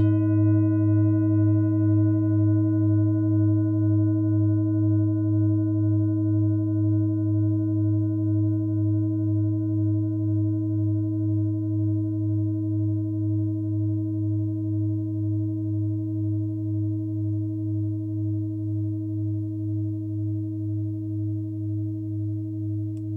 TIBET Klangschale Nr.45 Planetentonschale: Mondton
Klangschale TIBET Nr.45
Klangschale-Durchmesser: 29,8cm
Sie ist neu und ist gezielt nach altem 7-Metalle-Rezept in Handarbeit gezogen und gehämmert worden.
(Ermittelt mit dem Filzklöppel)
Auf unserer Tonleiter entspricht er etwa dem "Gis".
klangschale-tibet-45.wav